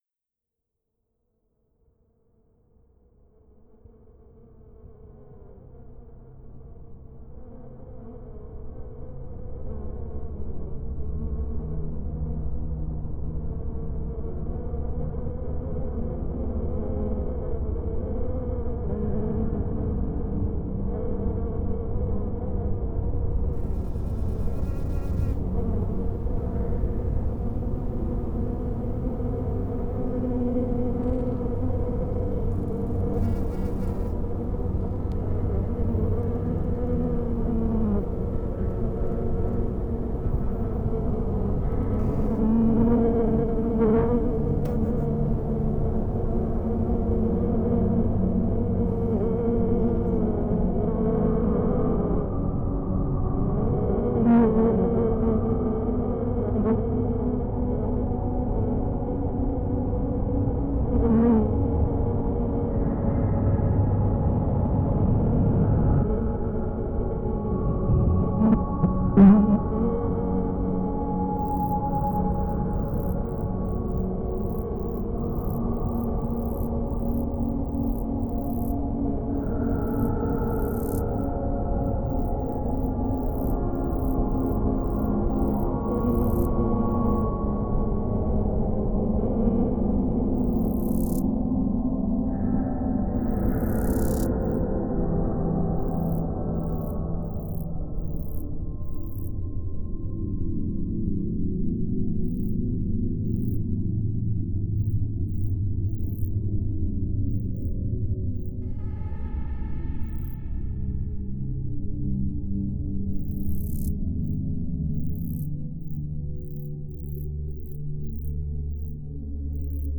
Cette composition sur le thème des insectes mélange des sons de diptères (mouches, syrphes) et d’hyménoptères (abeilles et frelons), ainsi que des instruments midi. L’idée était d’imaginer une sorte de monde post-apocalyptique où seuls les insectes auraient survécu.